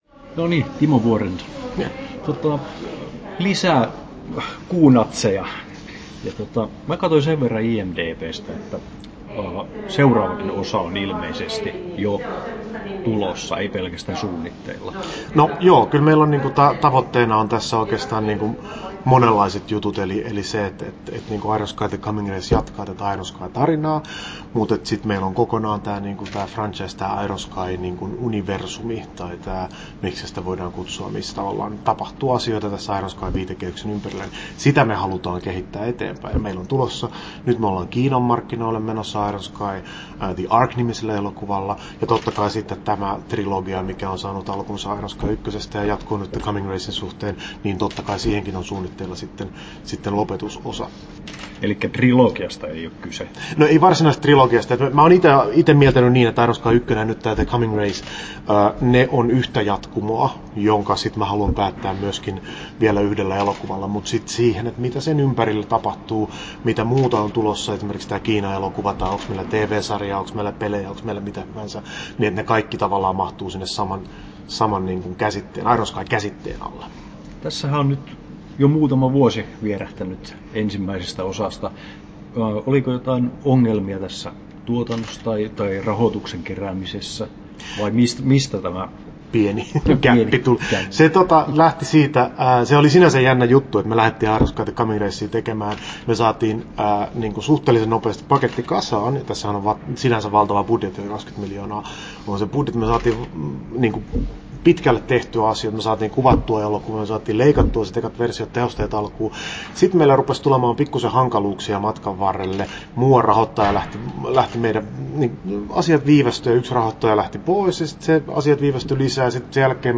Haastattelussa Timo Vuorensola Kesto: 9'41" Tallennettu: 10.01.2019, Turku Toimittaja